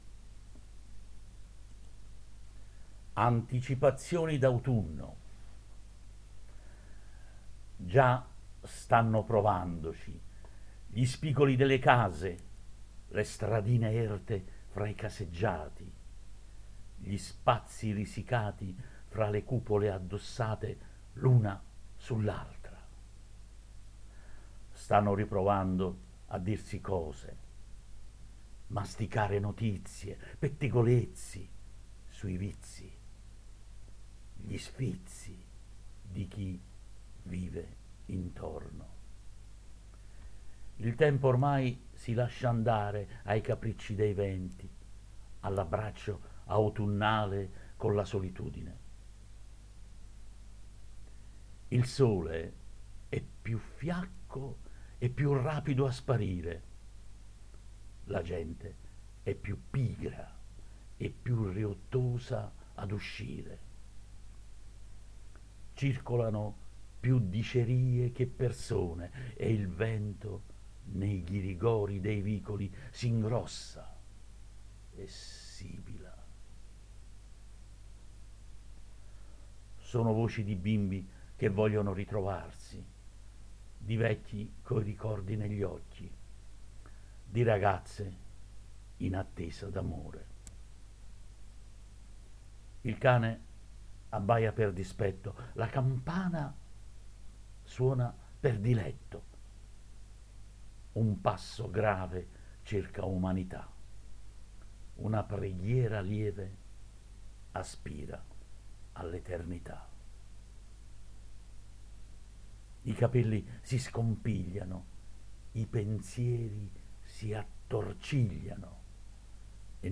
Poesia
Questa la versione recitata